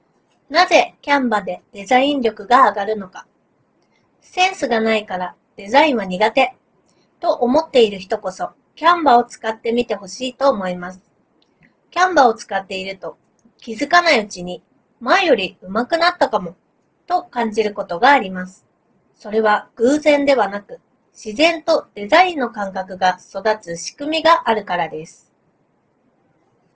▼Notta Memoのマイクで拾った音声
4つのMEMSマイクと骨伝導マイクを組み合わせた5マイク構成を採用しているおかげで、周囲の環境音を効果的に抑制し、話者の声をクリアに録音することができました。